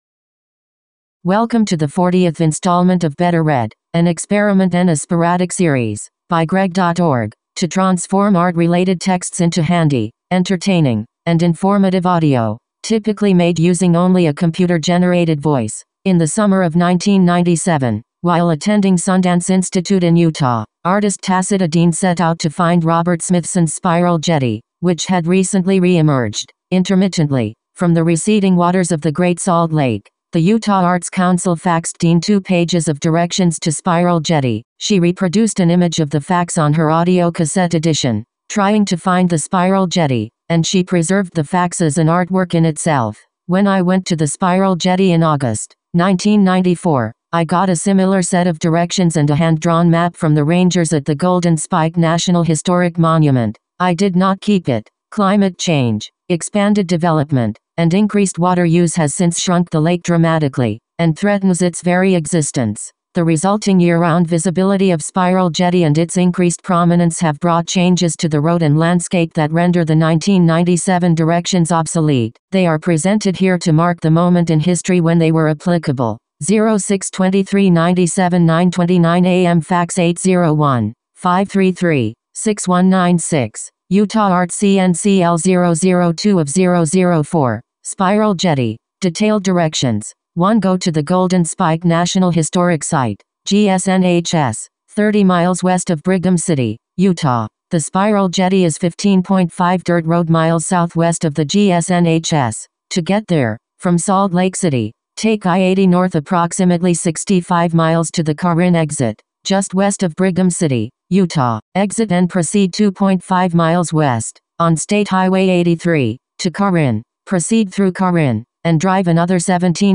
The text partially reproduced here is read in full in the audio below, but for conceptual reasons not explained in the audio, it felt relevant to have the directions interrupted by the header. hopefully this image gives a sense for what will happen in the robot audio performance.
This edition of Better Read is an audio performance of that those now-obsolete directions, as preserved in Dean’s artwork.